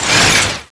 Index of /sound/icsdm_new/privilege/guns
cminigun_draw.wav